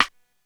Index of /90_sSampleCDs/300 Drum Machines/Korg DSS-1/Drums01/03
Rimshot.wav